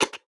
sfx_button.wav